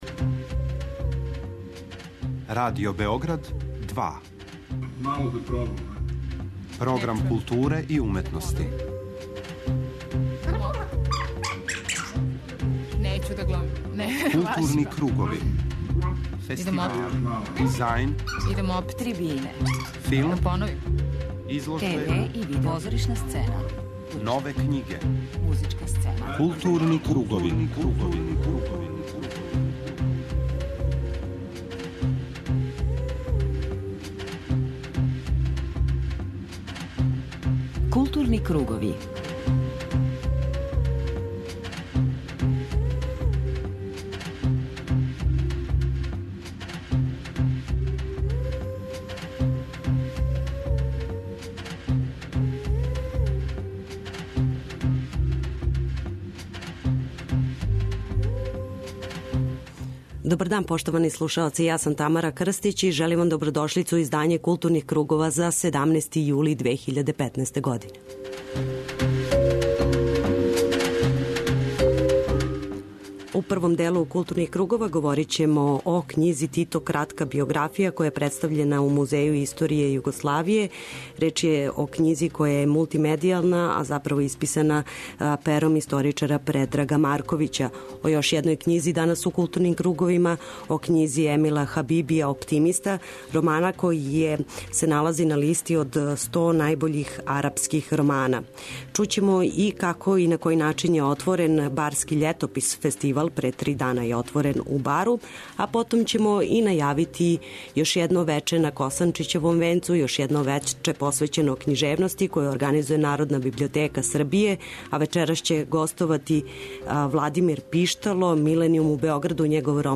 преузми : 51.29 MB Културни кругови Autor: Група аутора Централна културно-уметничка емисија Радио Београда 2.